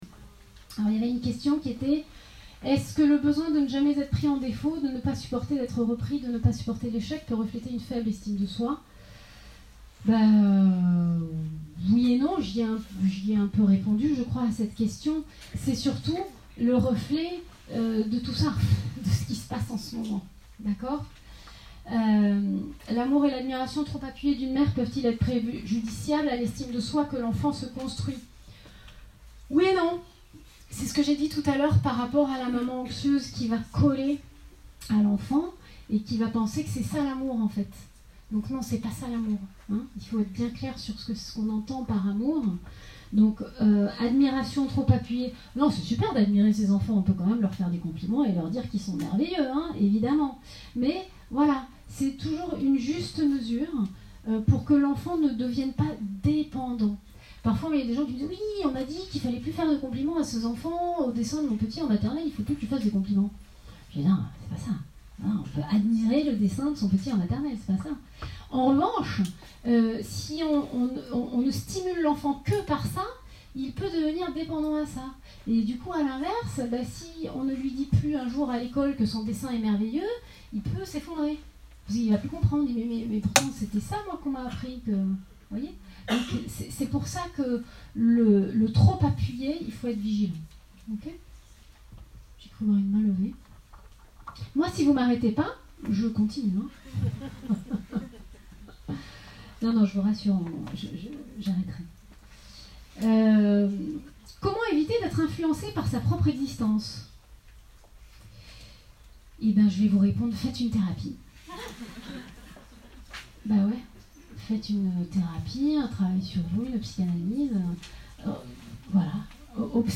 Ecouter la conférence sur l'estime de soi des ados